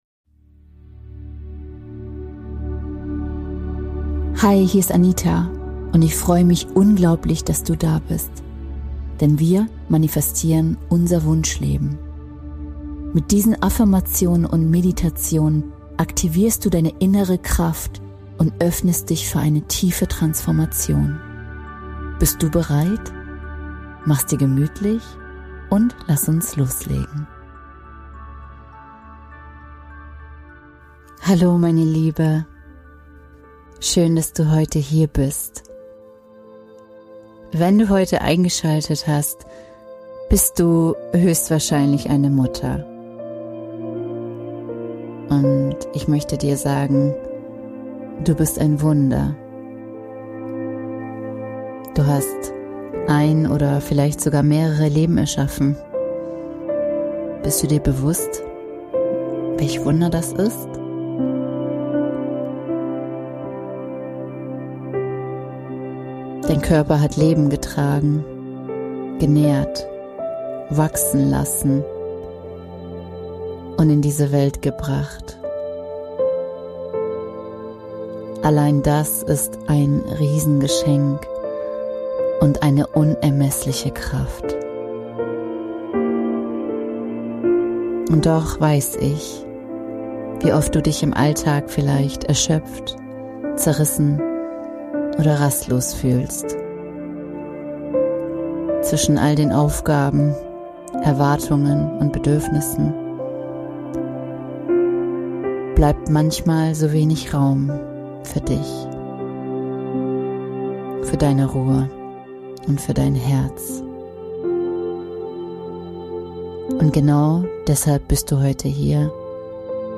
In dieser Meditation lade ich dich als Mama, ein, dir einen Augenblick ganz für dich selbst zu schenken.
Mit sanften Affirmationen, liebevoller Begleitung und bewusstem Atem findest du Schritt für Schritt zurück zu deiner Mitte – zu Ruhe, Gelassenheit und Verbundenheit mit dir selbst.